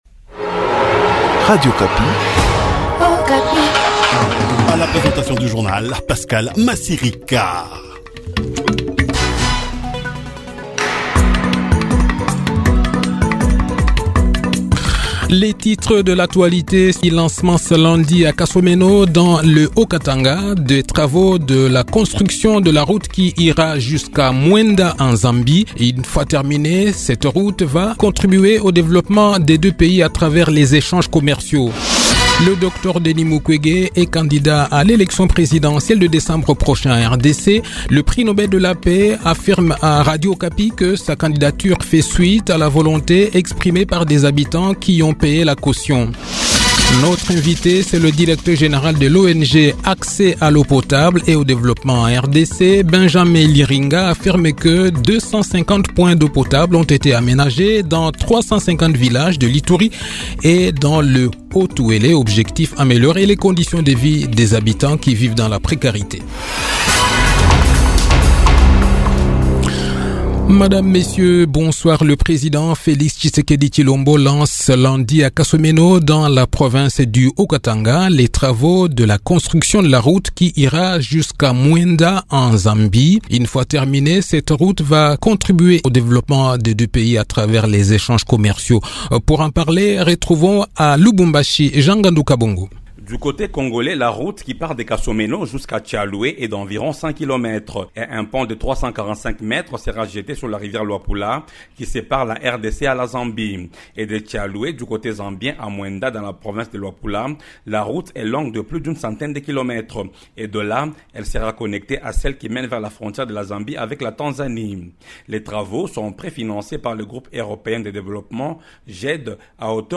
Le journal de 18 h, 2 octobre 2023